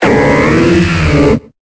Cri de Sepiatroce dans Pokémon Épée et Bouclier.